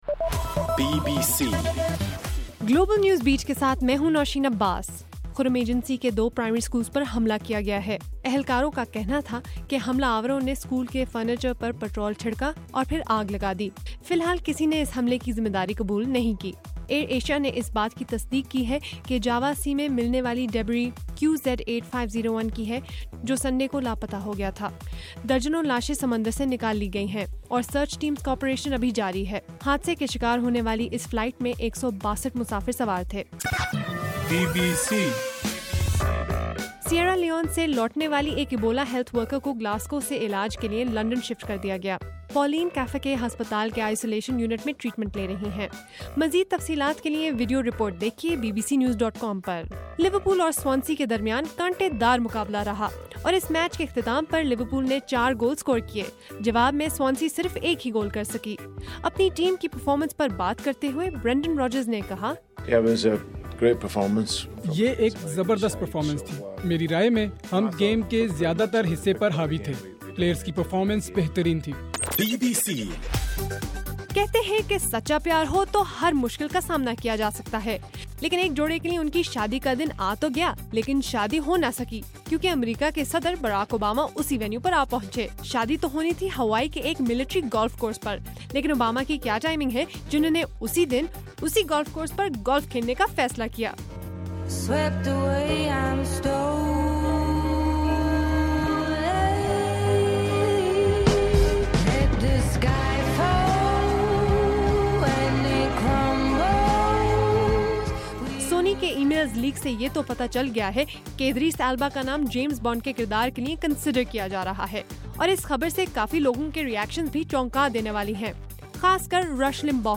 دسمبر 30: رات 11 بجے کا گلوبل نیوز بیٹ بُلیٹن